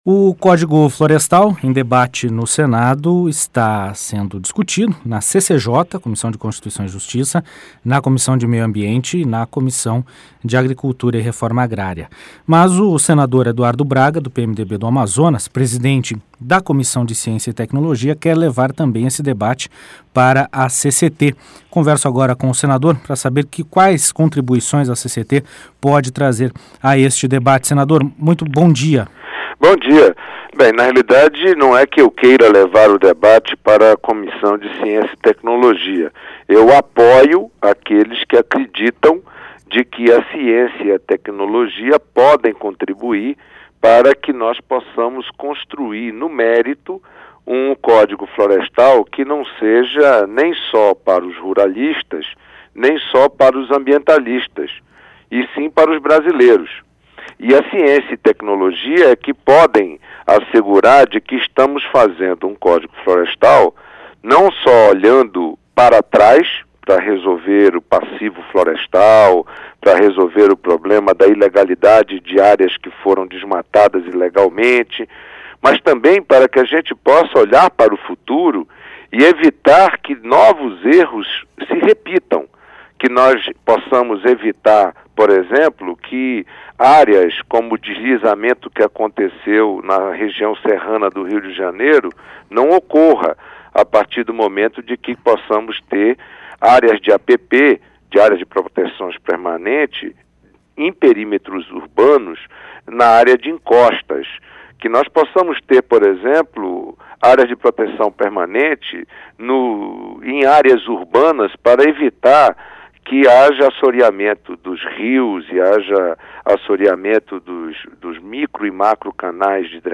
Entrevista com o senador Eduardo Braga (PMDB-AM), presidente da Comissão de Ciência e Tecnologia.